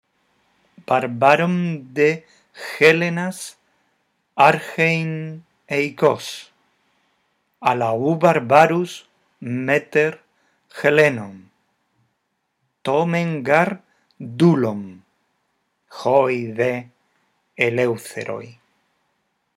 Antes de analizar el fragmento de Los Persas, te proponemos que escuches la lectura de los versos en los que Eurípides habla de la libertad de los griegos.